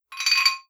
Impacts
clamour9.wav